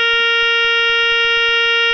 ZUMBADOR - SONIDO CONTINUO
Zumbador electromagnético de fijación base/mural
Sonido continuo